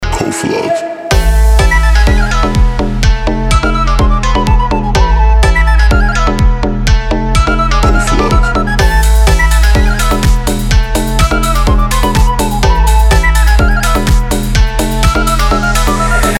• Качество: 320, Stereo
мужской голос
восточные мотивы
Dance Pop